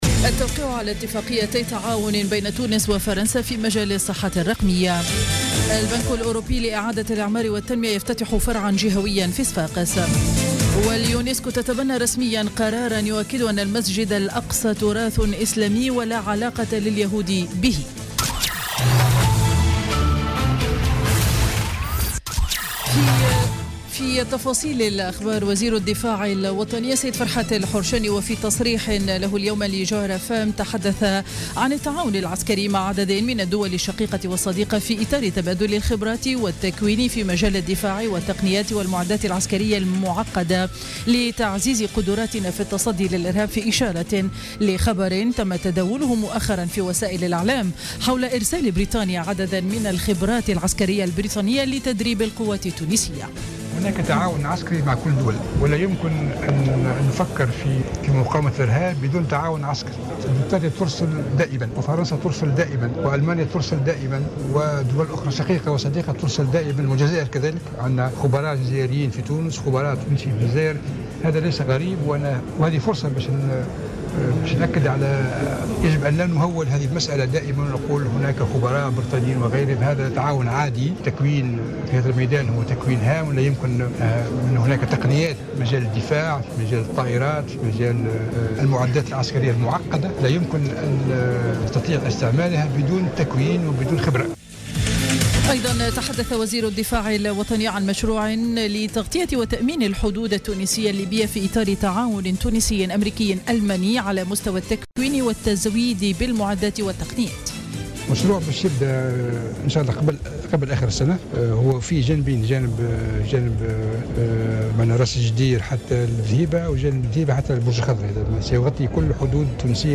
نشرة أخبار السابعة مساء ليوم الثلاثاء 18 أكتوبر 2016